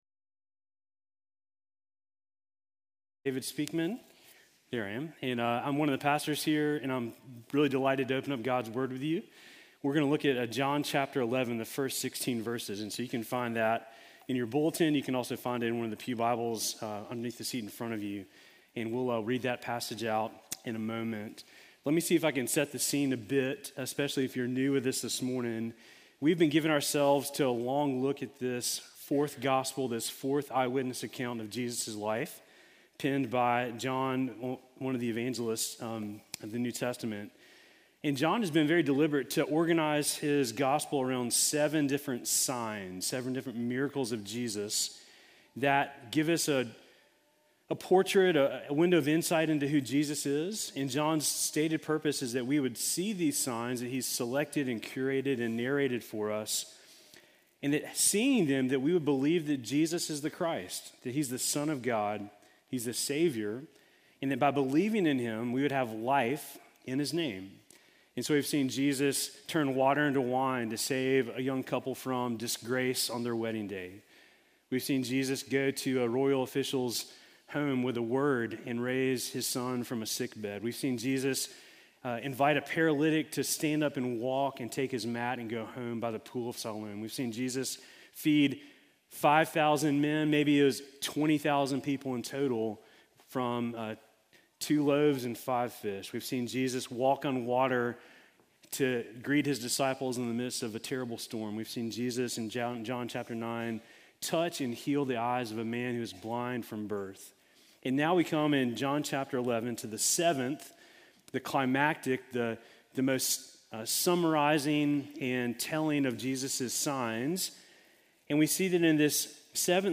Sermon from November 9